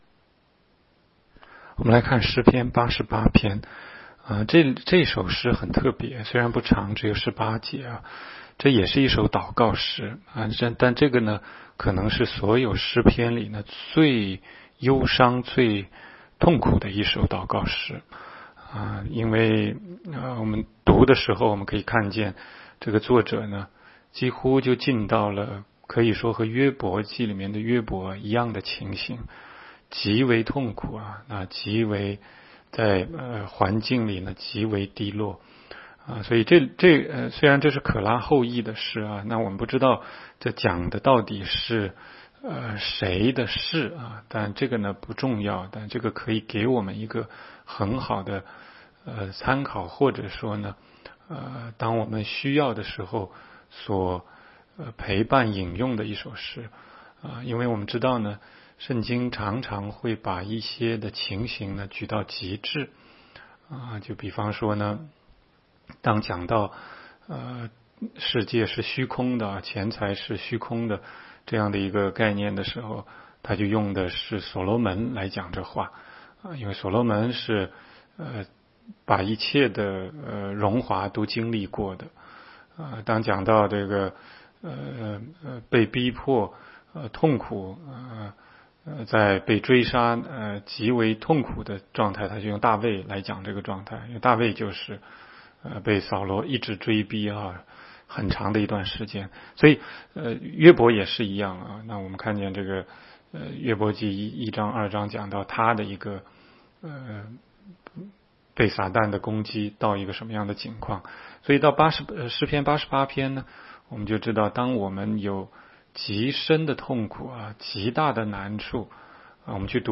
16街讲道录音 - 每日读经-《诗篇》88章